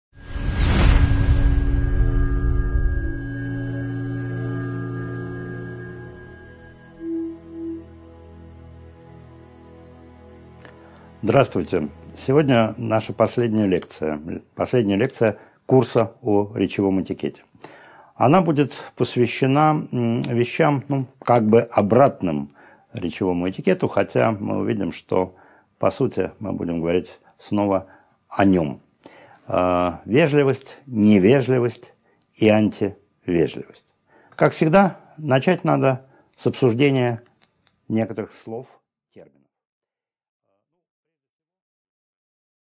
Аудиокнига 10.2 Несколько определений и вопросов | Библиотека аудиокниг